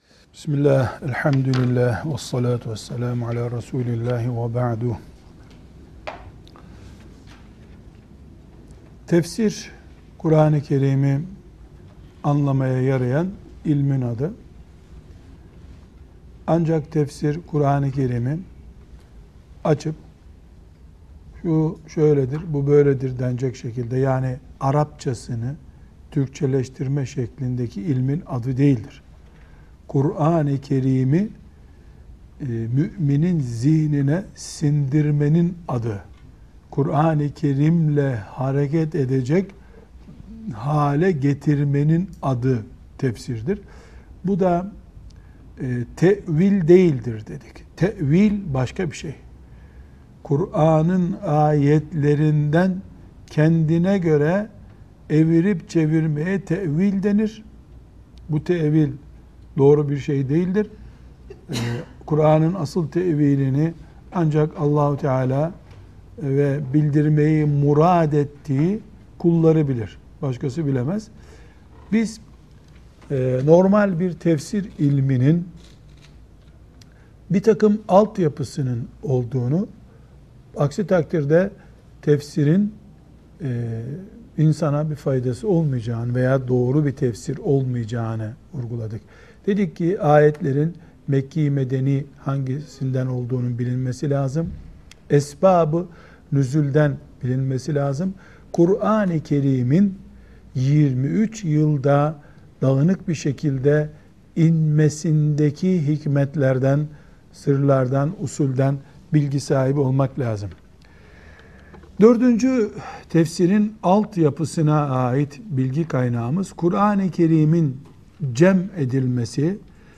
3) Tefsir Dersleri 3. Bölüm | Sosyal Doku TV